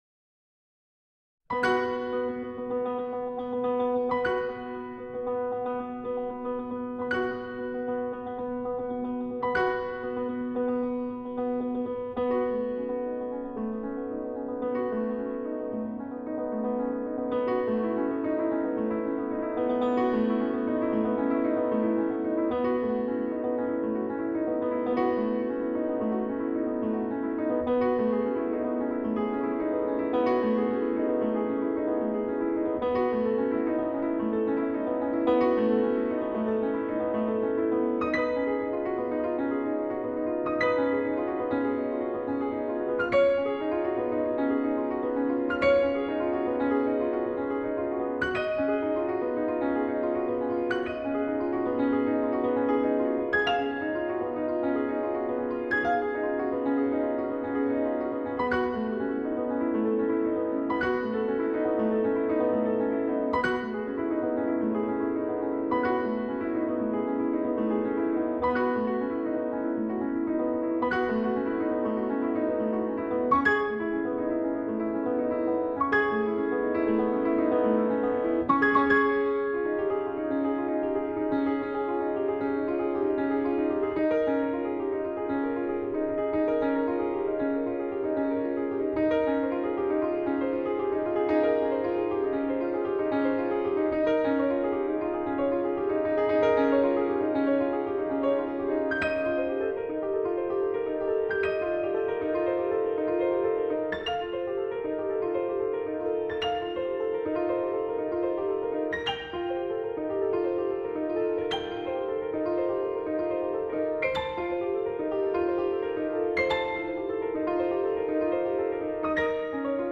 played with the idea of perpetual motion and palindrome